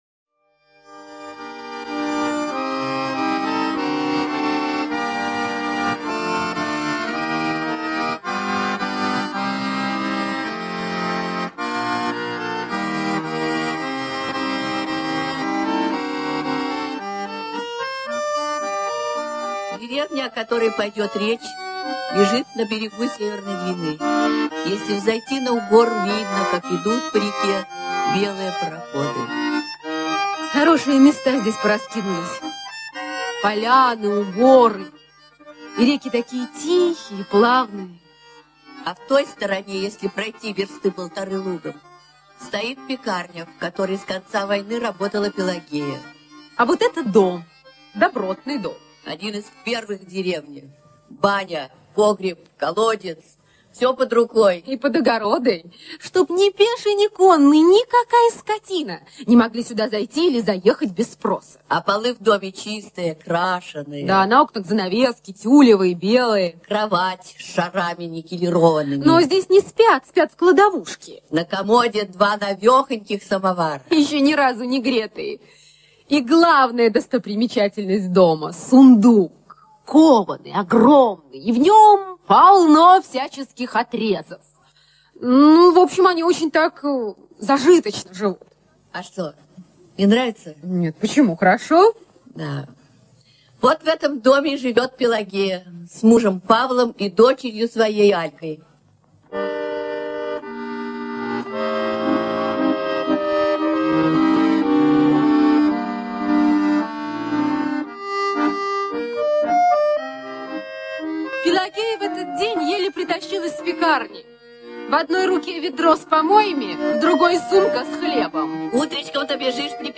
На данной странице вы можете слушать онлайн бесплатно и скачать аудиокнигу "Пелагея" писателя Фёдор Абрамов.